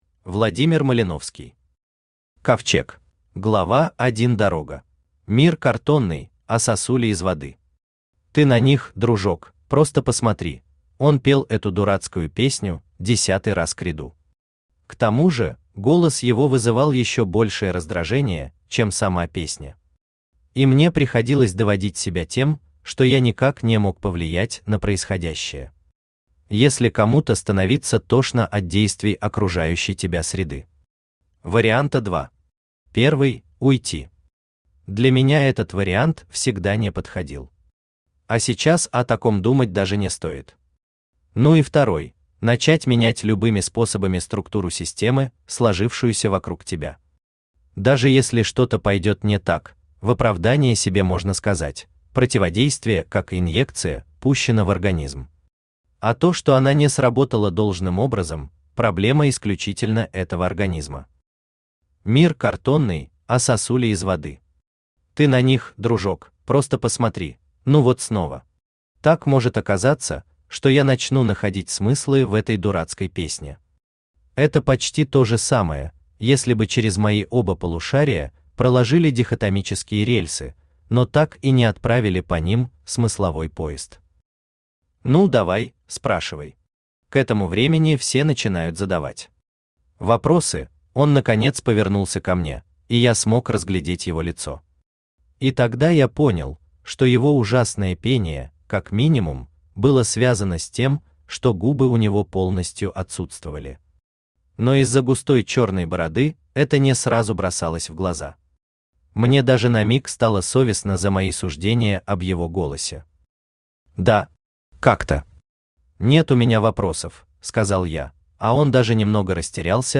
Аудиокнига Ковчег | Библиотека аудиокниг
Aудиокнига Ковчег Автор Владимир Малиновский Читает аудиокнигу Авточтец ЛитРес.